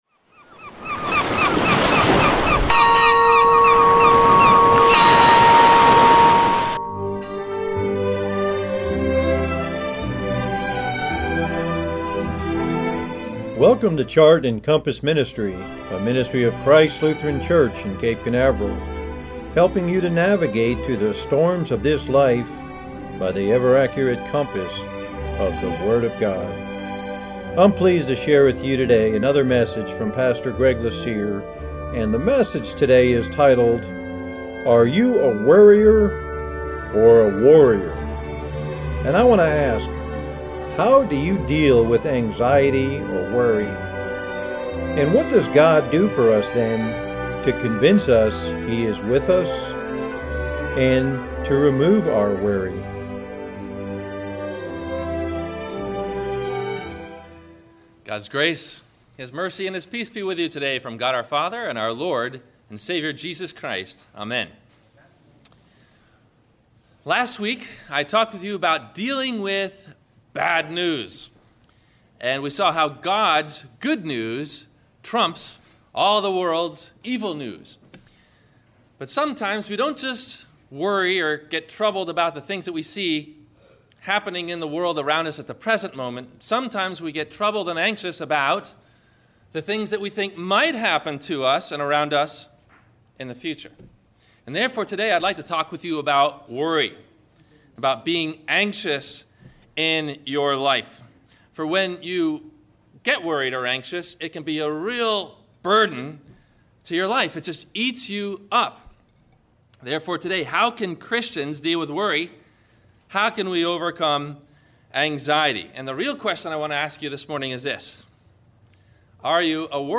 Are You a Worrier or a Warrior? – WMIE Radio Sermon – June 18 2018 - Christ Lutheran Cape Canaveral